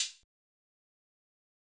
Closed Hats / Open Mind Hi Hat